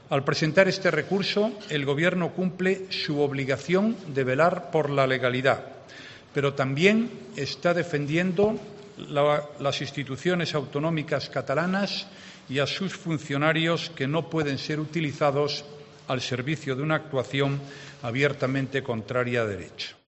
Así lo ha anunciado en rueda de prensa el presidente del Gobierno, tras el Consejo de Ministros que ha analizado el informe del Consejo de Estado que avala el recurso de inconstitucionalidad por entender que el nuevo reglamento de la Cámara catalana vulnera el derecho de participación política en condiciones de igualdad reconocido en el artículo 23.2 de la Constitución.